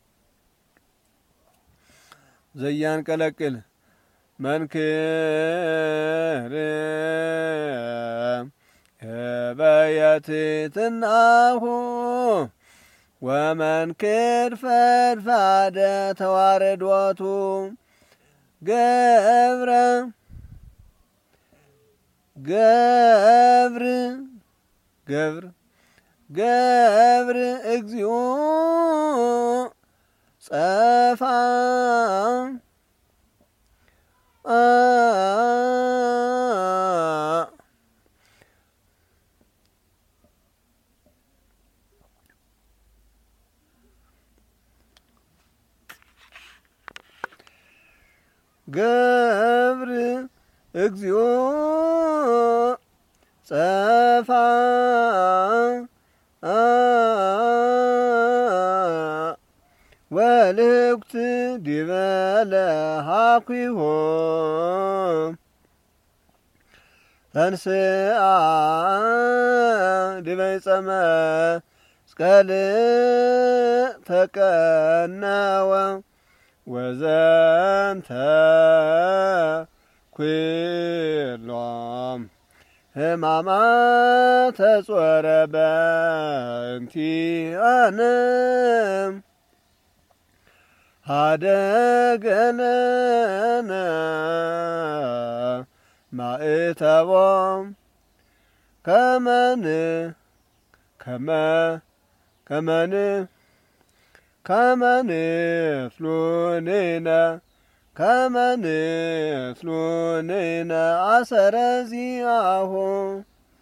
churchmusic